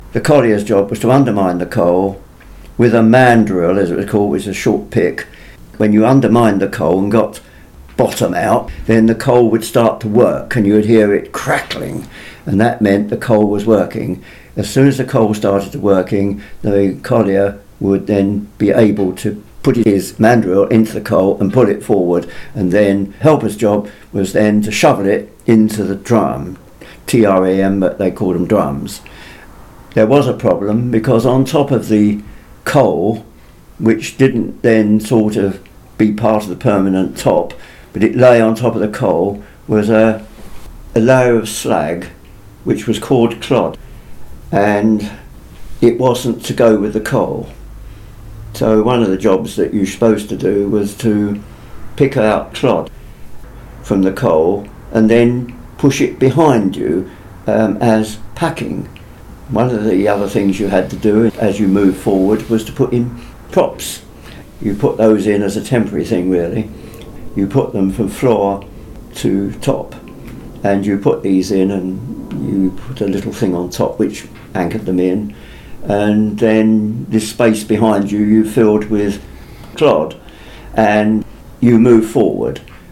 South Wales recalled by a Bevin Boy